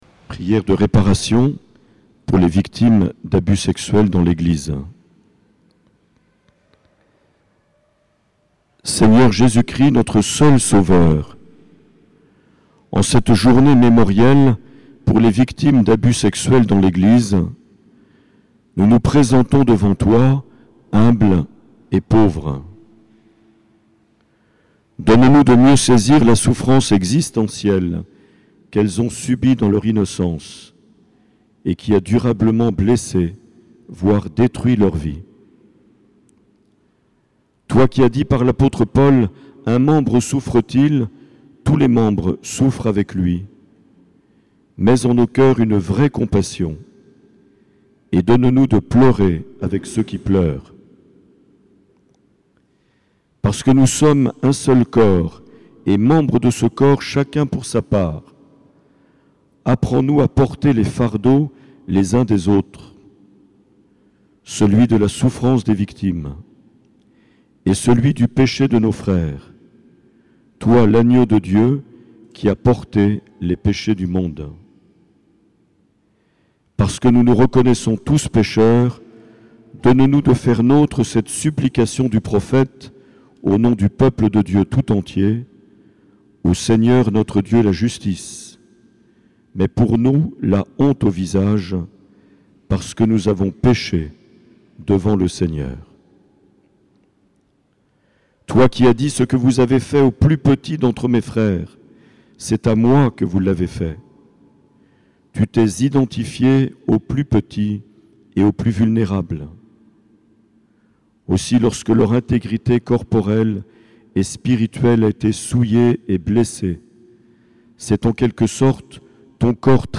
Prière de réparation enregistrée le 20 mars en la cathédrale sainte Marie de Bayonne.